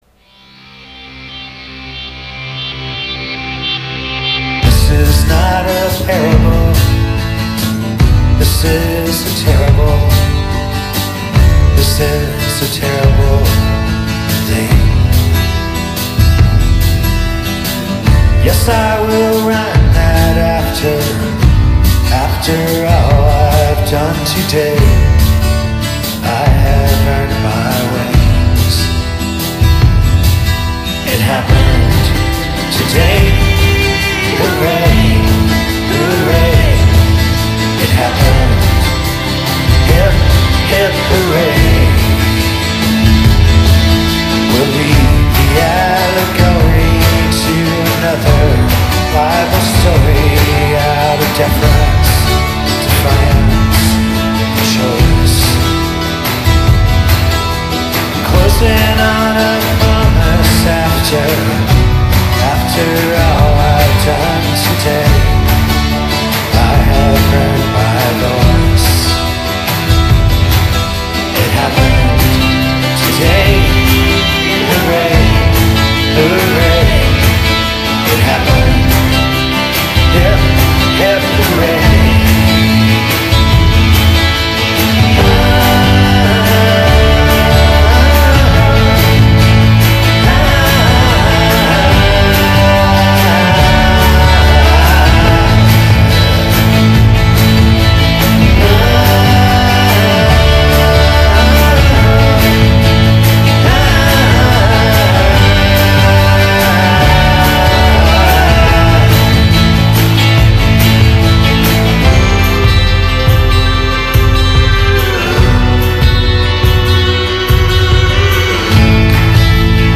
chitarra acustica e percussioni minimali